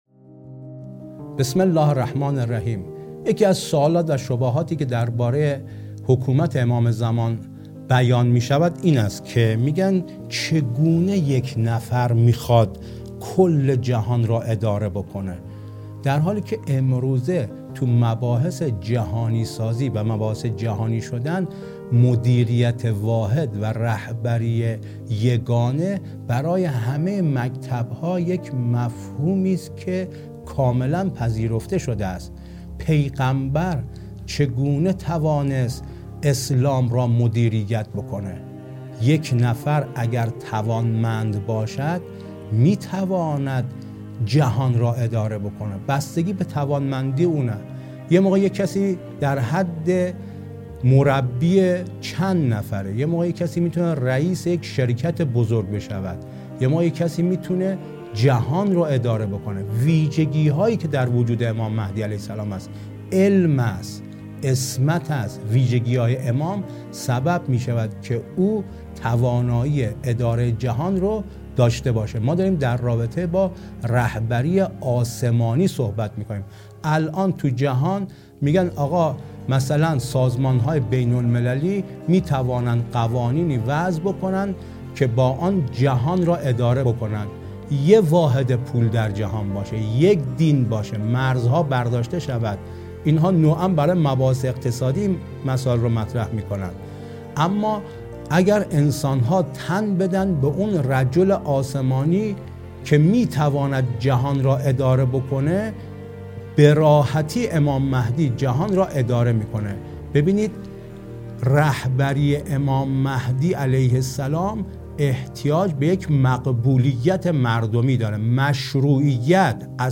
در گفتگویی تخصصی با رسانه حوزه به تبیین و پاسخ‌گویی پیرامون شبهات مهدویت پرداخته که سومین جلسه آن تقدیم شما فرهیختگان می شود.